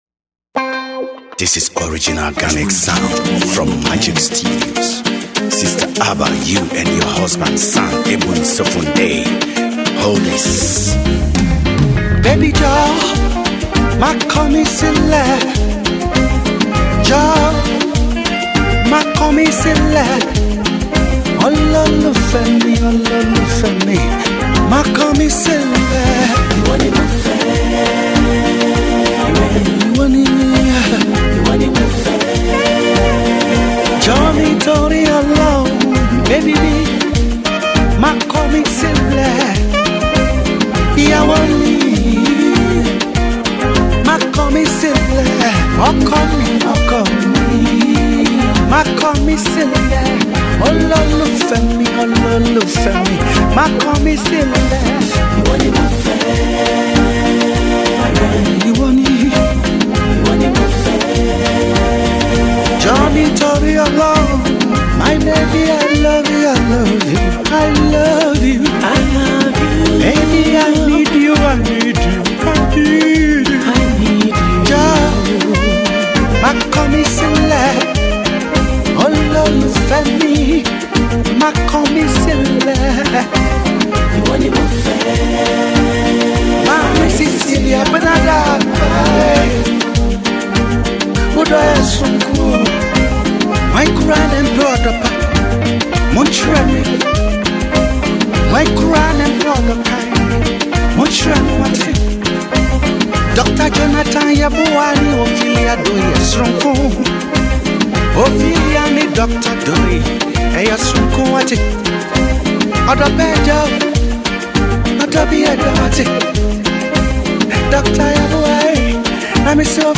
Veteran Ghanaian Highlife Singer/Songwriter